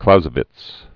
(klouzə-vĭts), Carl von 1780-1831.